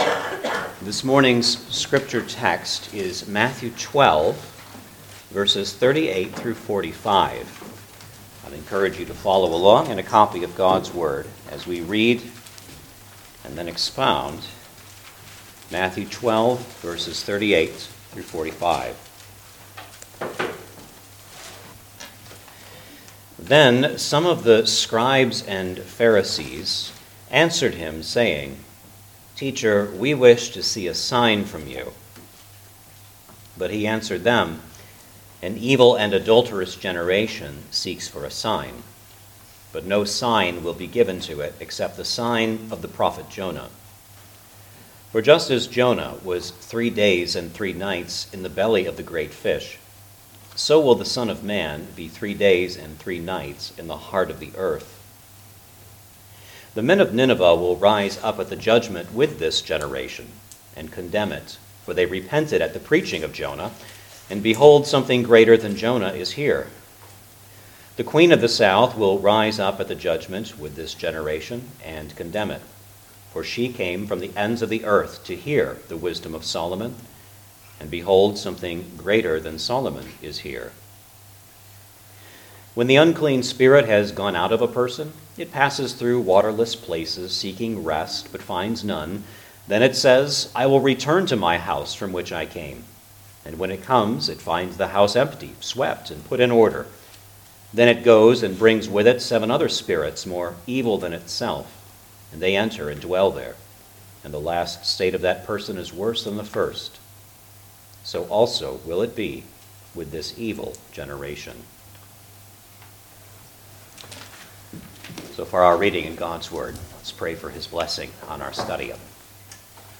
Gospel of Matthew Passage: Matthew 12:38-45 Service Type: Sunday Morning Service Download the order of worship here .